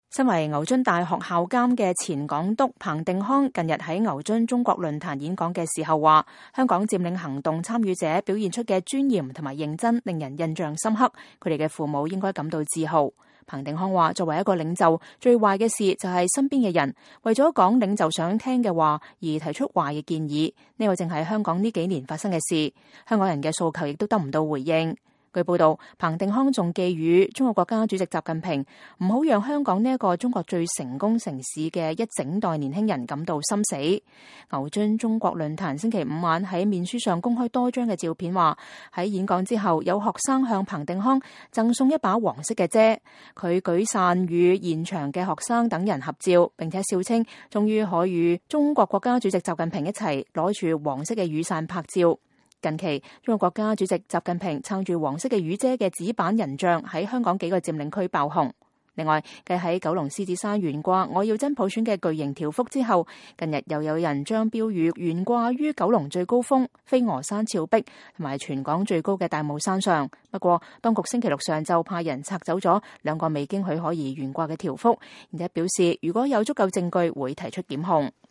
身為牛津大學校監的前港督彭定康近日在牛津中國論壇演講時表示，香港佔領行動參與者表現出的尊嚴和認真，他們的父母應感到自豪。彭定康說，作為一個領袖，最壞的事就是身邊的人，為了說領袖想聽的話，而提出壞建議，這正是香港這幾年發生的事情，港人的訴求也得不到回應。